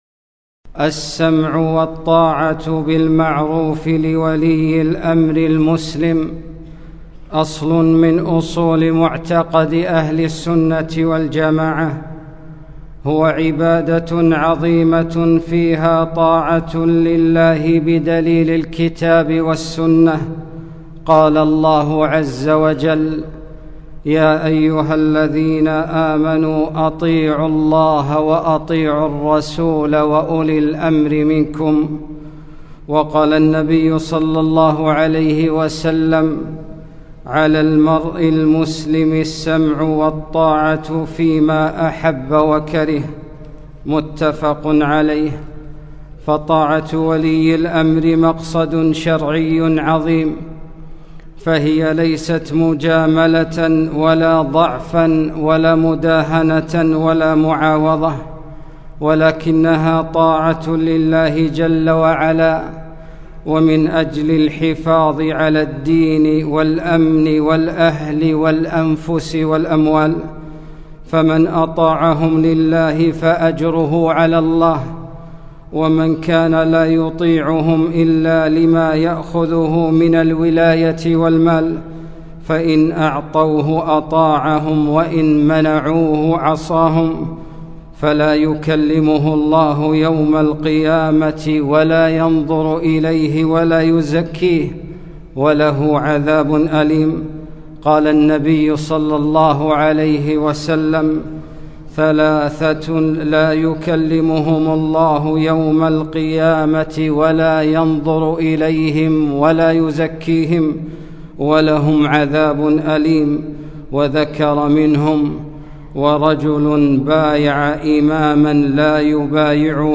خطبة - السمع والطاعة لولي الأمر